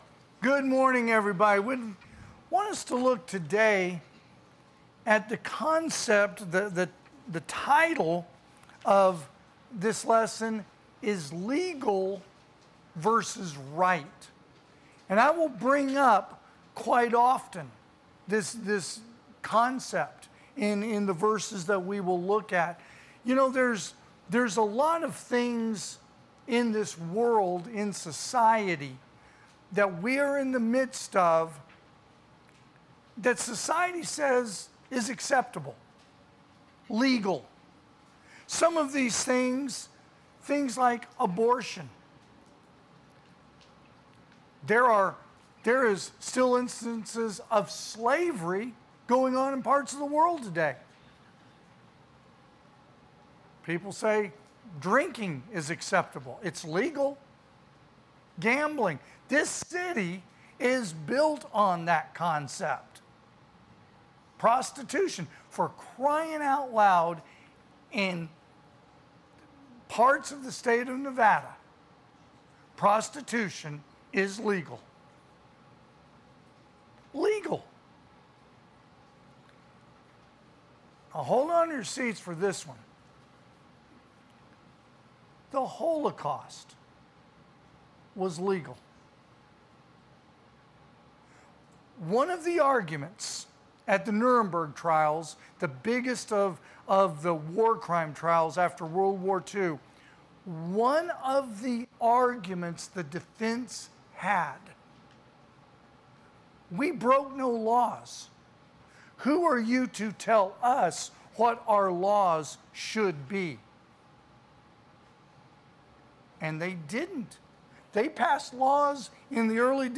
2025 (AM Worship) "Legal vs Right"
Sermons